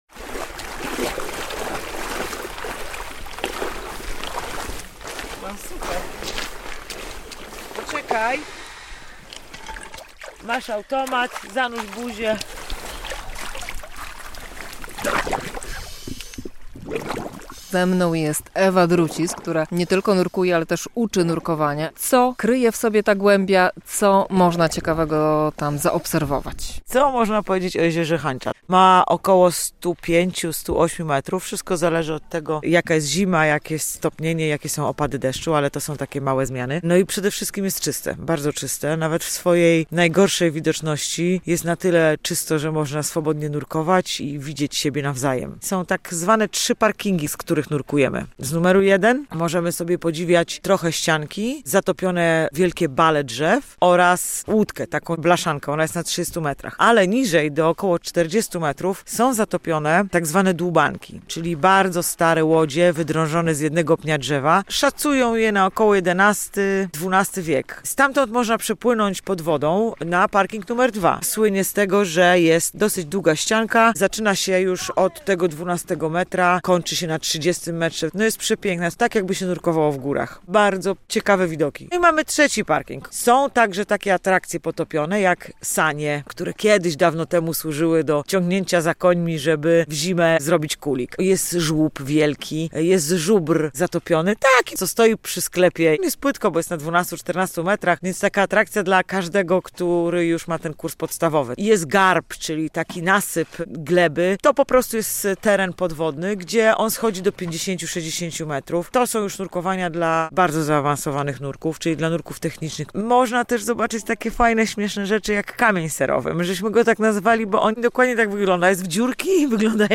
Jezioro Hańcza - najgłębsze jezioro w Polsce - relacja